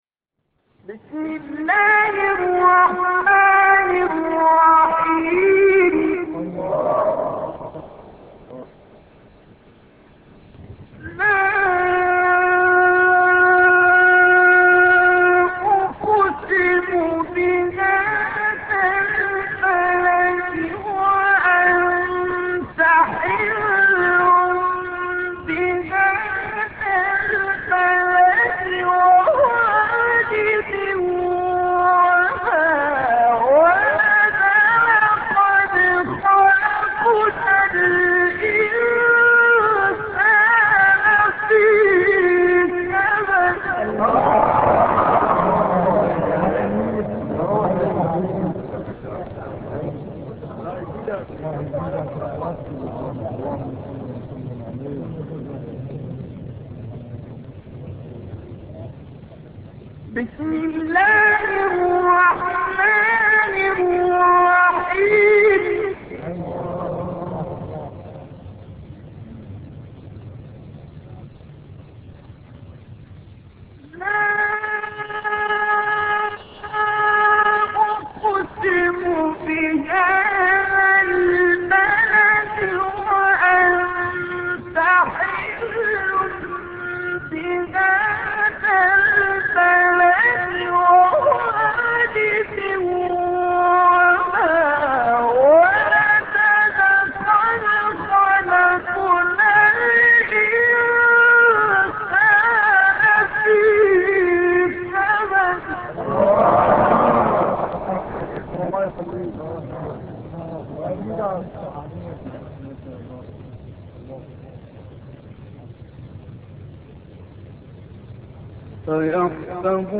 تلاوت آیاتی از سوره بلد توسط استاد عبدالباسط محمد عبدالصمد